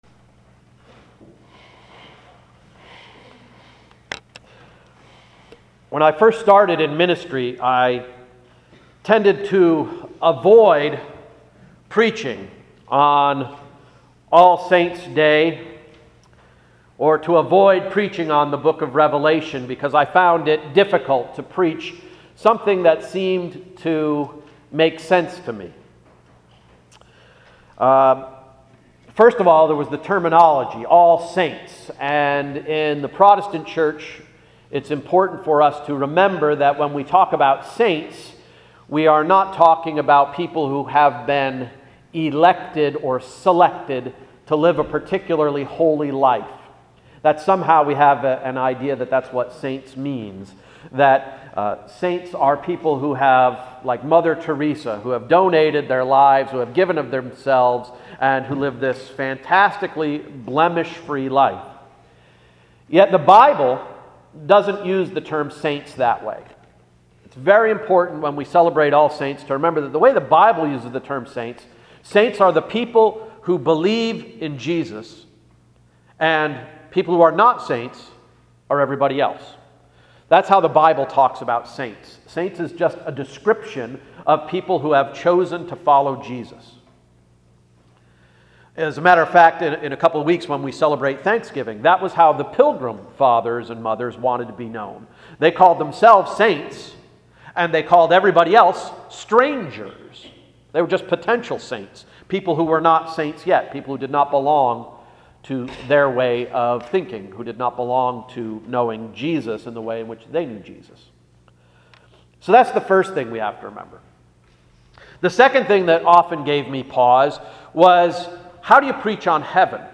Sermon of November 2, 2014–“Walking in White”